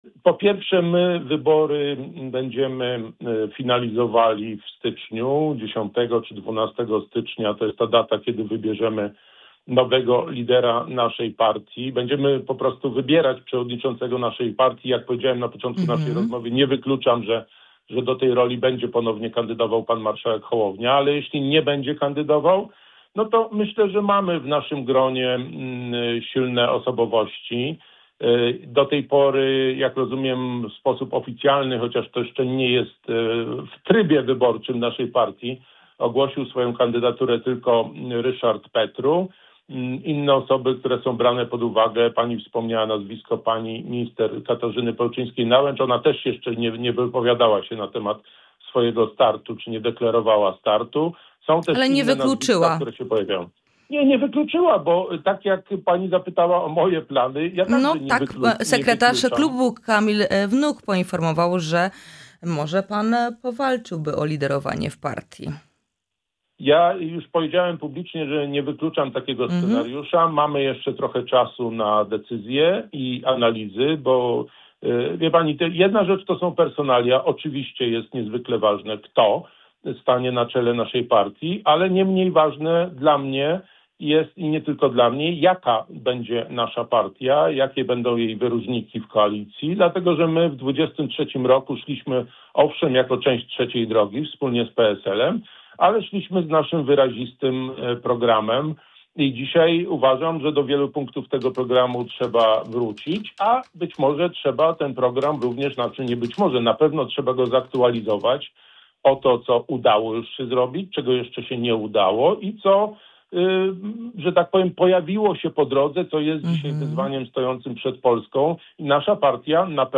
O sytuacji w partii, potencjalnych odejściach, ewentualnej tece wiceministra, a także głosowaniu nad odwołaniem przewodniczącej Komisji Europejskiej rozmawiamy z europosłem Krzysztofem Kobosko.